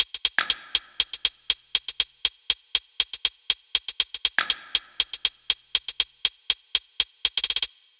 Bp Cl Hats Loop.wav